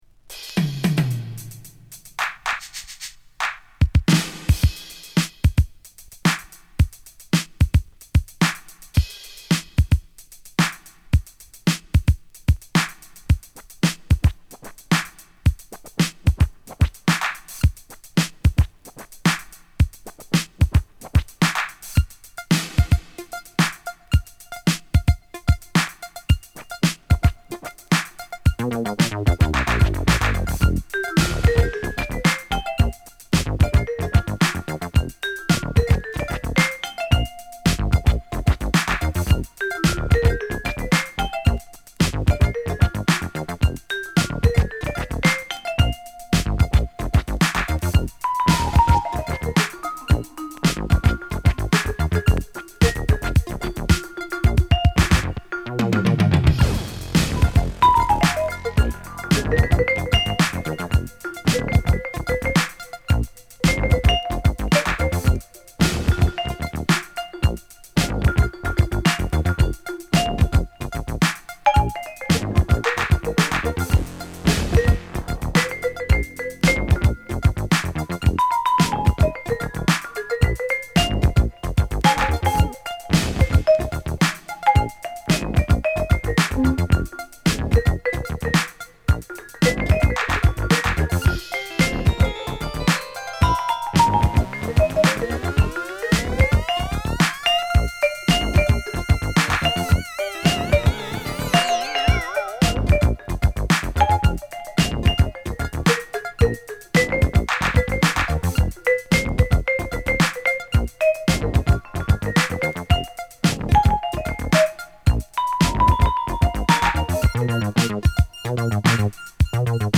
この時代らしいエレクトロビートにヴィブラフォンを絡めた異色のエレクトロジャズ！